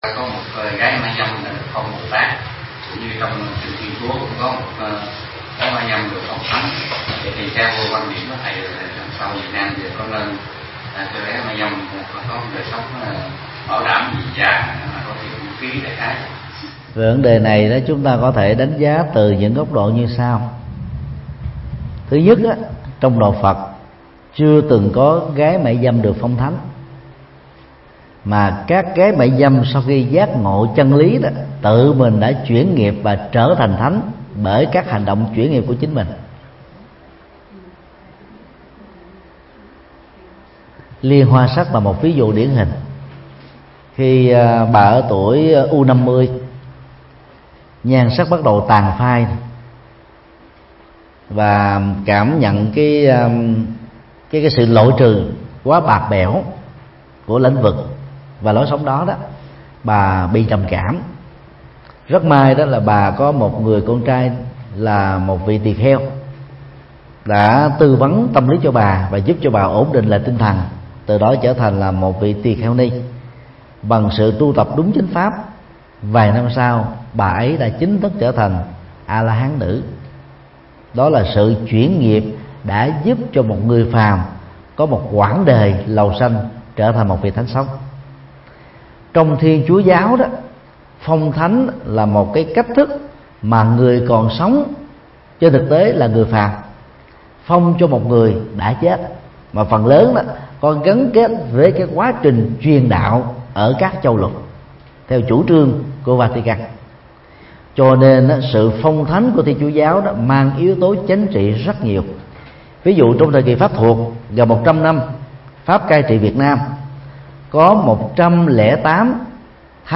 Mp3 Vấn đáp: nghề mại dâm, cờ bạc – Thầy Thích Nhật Từ Giảng tai chùa Linh Phong, Thụy Sĩ, ngày 4 tháng 7 năm 2015